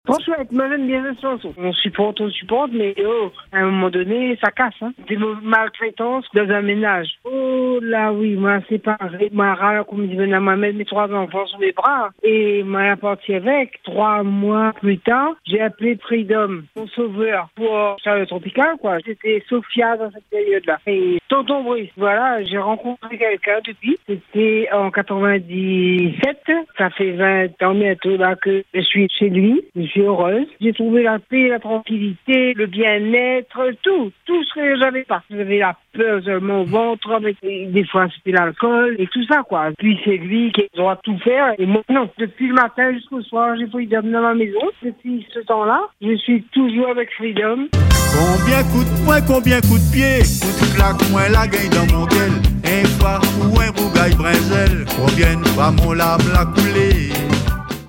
Derrière cette demande musicale, un parcours marqué par la maltraitance et les blessures du passé. Des épreuves difficiles, qu’elle évoque avec pudeur, mais aussi avec la force de celle qui a réussi à se relever.